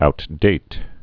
(out-dāt)